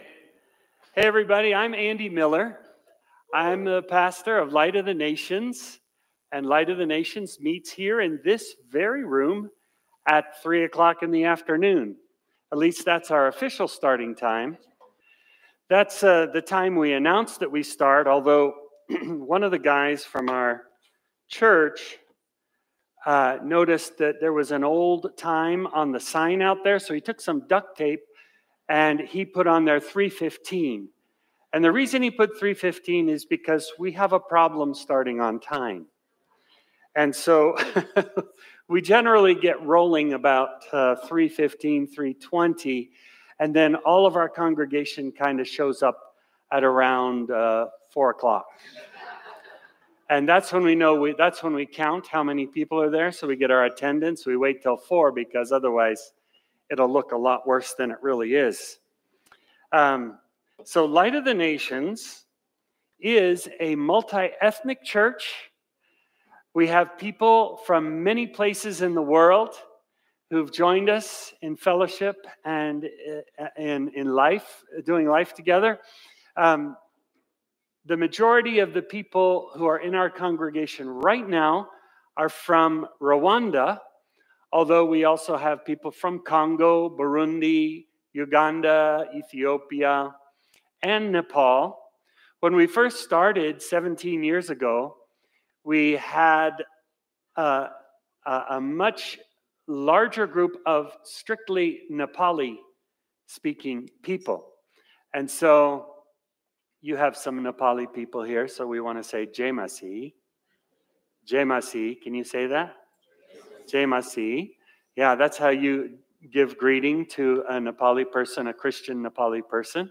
Sermon from Celebration Community Church on August 10, 2025